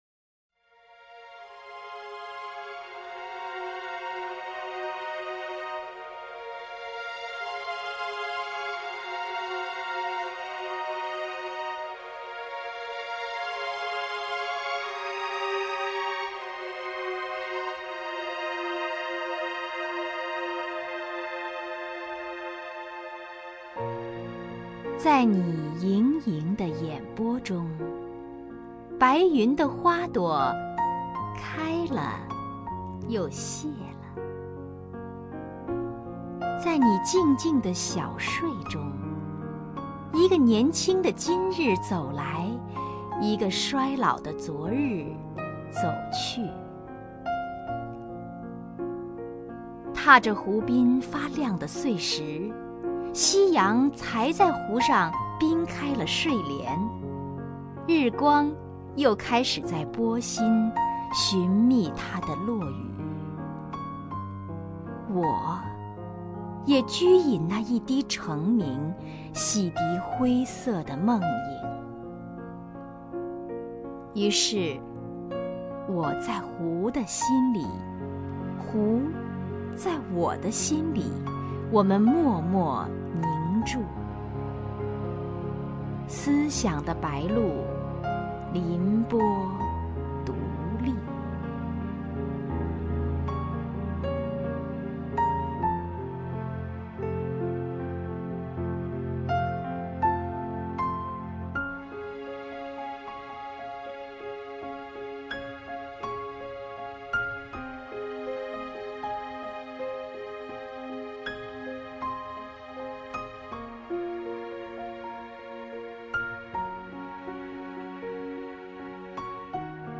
首页 视听 名家朗诵欣赏 王雪纯
王雪纯朗诵：《湖》(张秀亚)